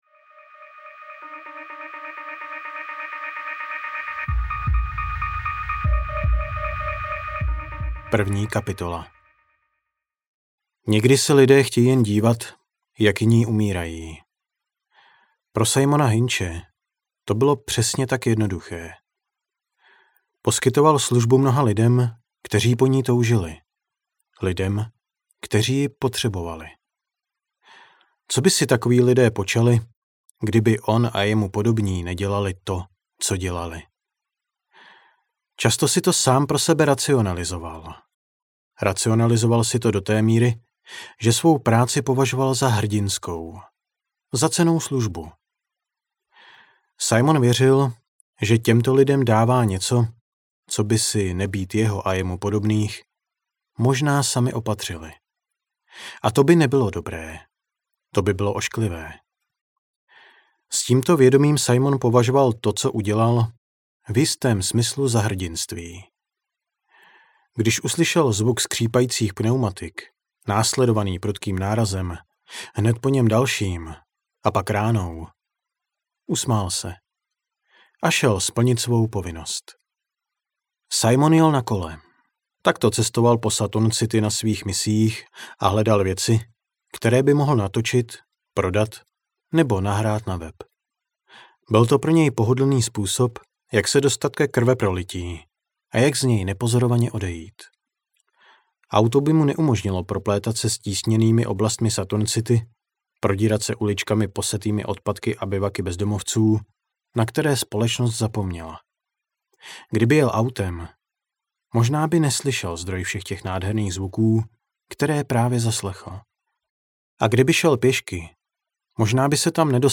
Virální životy audiokniha
Ukázka z knihy